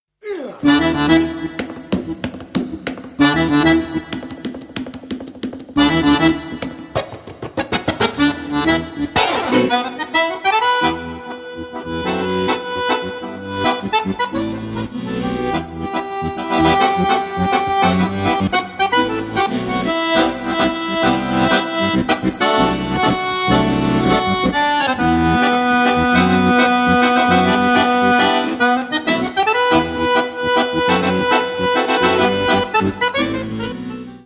freely improvising variations for each song